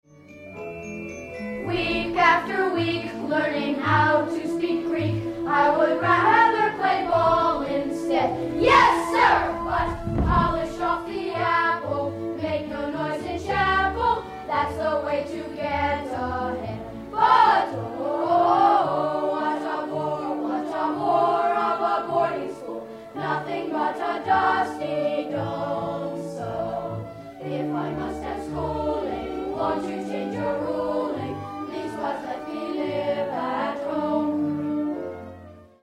(©1971 J. Adrian Verkouteren) excerpts from major songs were recorded live